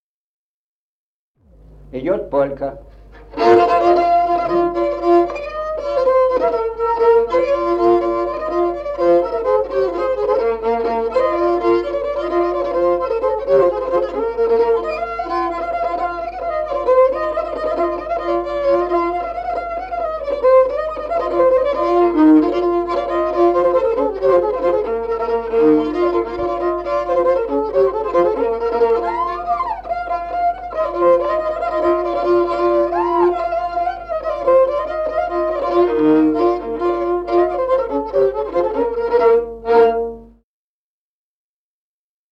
Музыкальный фольклор села Мишковка «Полька», репертуар скрипача.